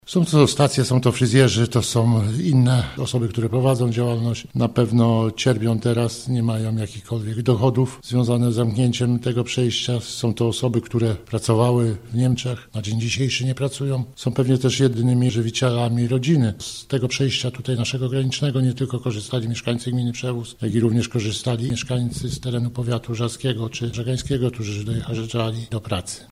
– To trudny czas dla nas wszystkich, ale na pograniczu jest on szczególnie dokuczliwy – mówi Mariusz Strojny, wójt Przewozu: